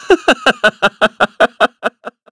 Shakmeh-Vox_Happy4_kr.wav